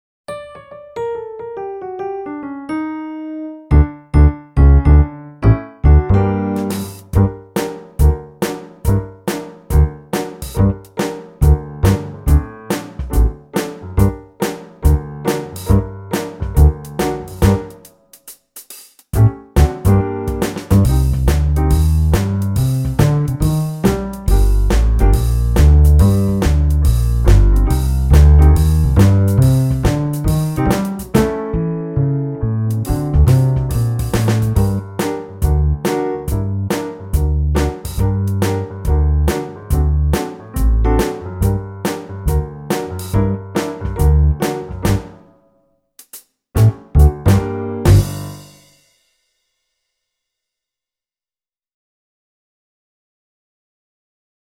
Don’t be put off by the tempo (we needed to play and count slowly today!)